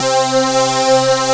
SILVAPAD2.wav